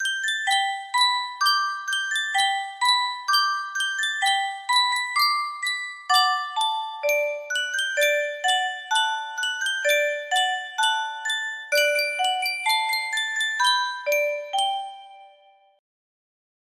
Yunsheng Music Box - Polly Wolly Doodle 6745 music box melody
Full range 60